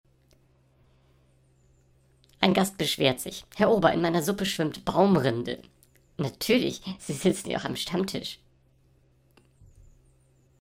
Vorgetragen von unseren attraktiven SchauspielerInnen.